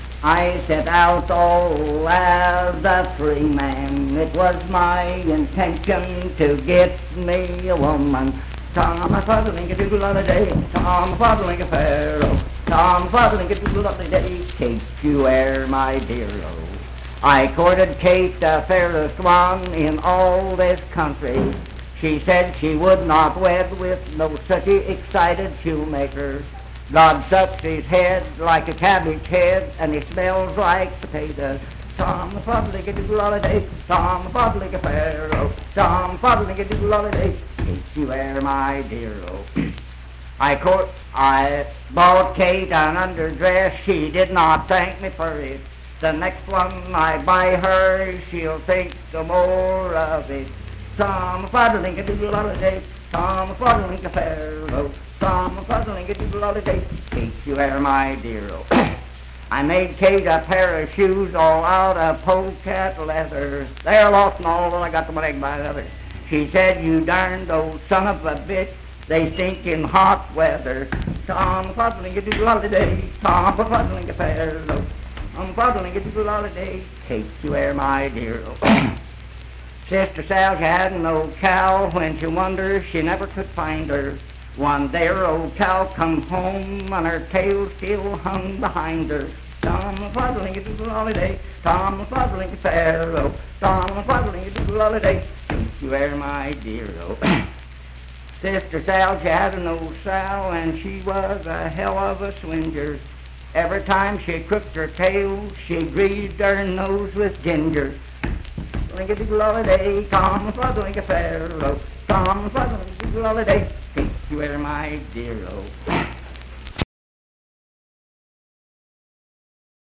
Unaccompanied vocal music performance
Verse-refrain 6 (8w/R).
Voice (sung)